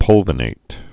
(pŭlvə-nāt) also pul·vi·nat·ed (-nātĭd)